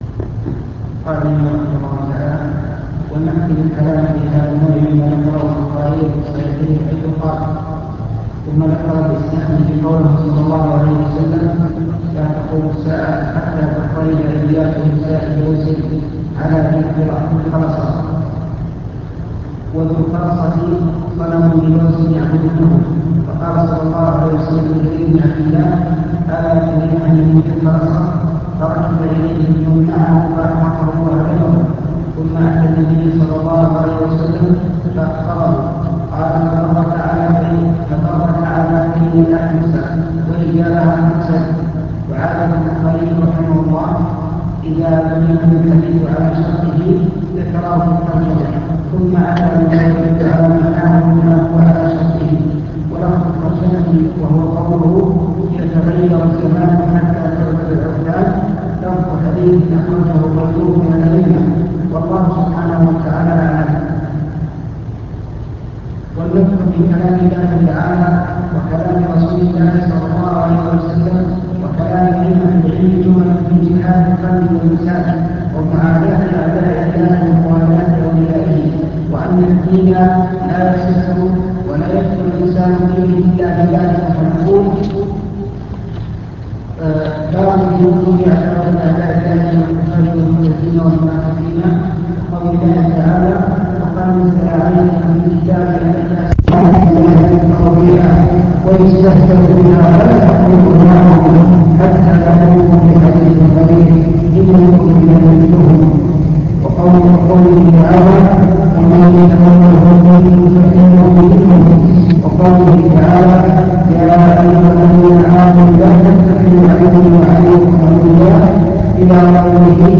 المكتبة الصوتية  تسجيلات - كتب  شرح كتاب مفيد المستفيد في كفر تارك التوحيد